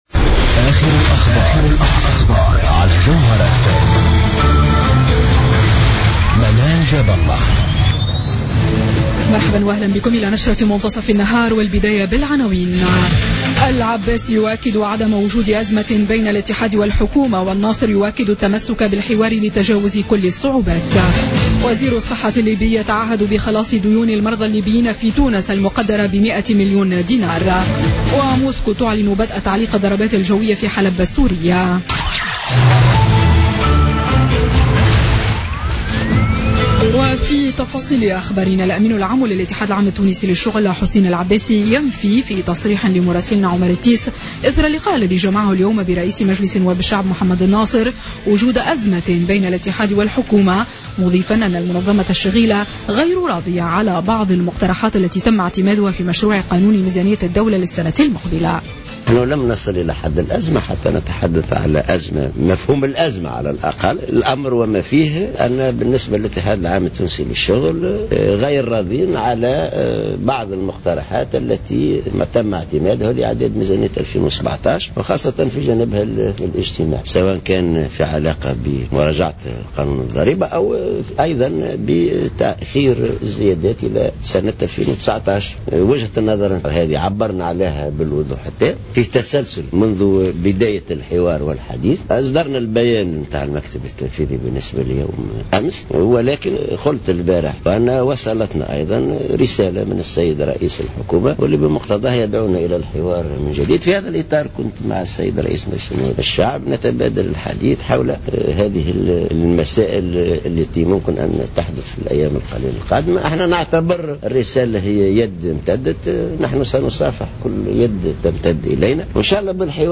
نشرة أخبار منتصف النهار ليوم الثلاثاء 18 أكتوبر 2016